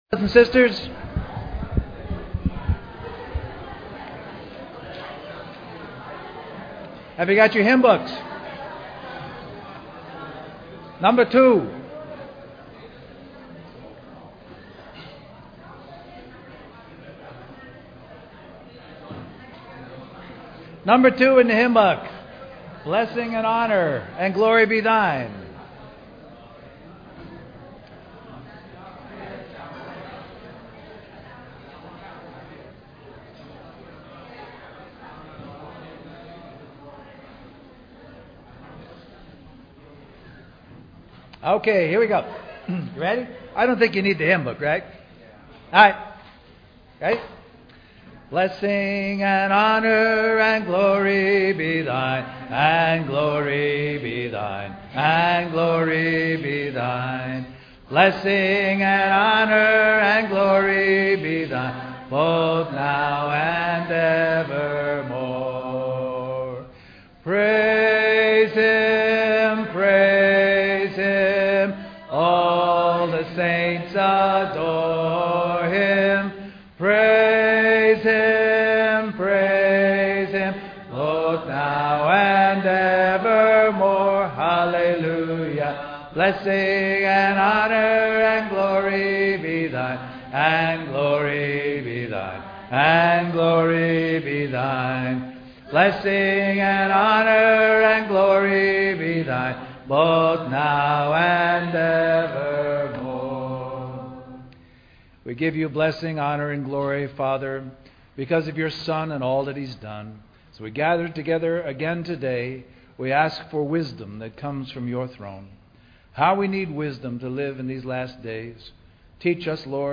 A collection of Christ focused messages published by the Christian Testimony Ministry in Richmond, VA.
West Coast Christian Conference